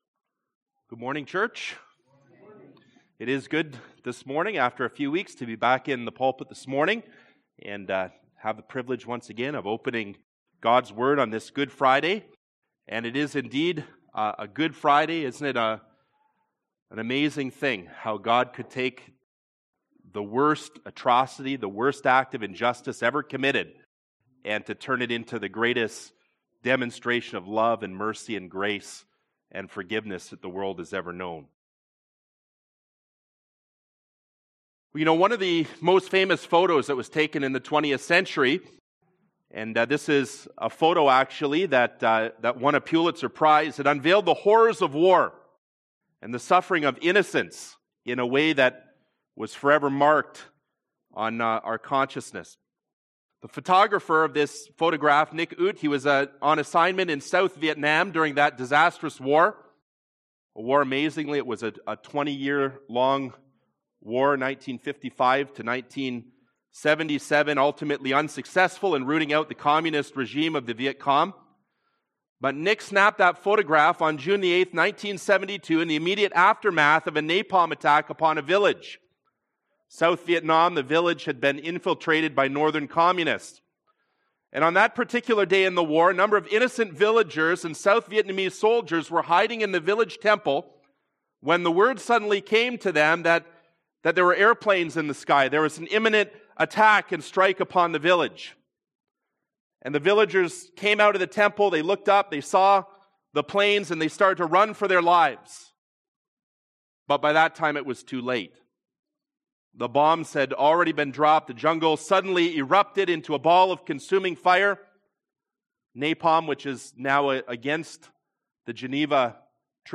Good Friday Sermon